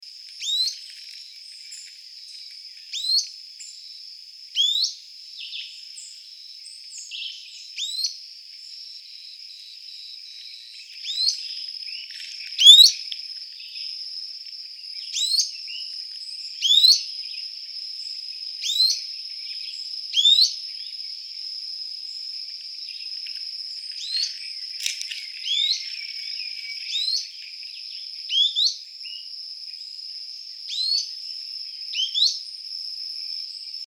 Ochre-cheeked Spinetail (Synallaxis scutata)
Life Stage: Adult
Country: Argentina
Location or protected area: Parque Nacional Calilegua
Condition: Wild
Certainty: Observed, Recorded vocal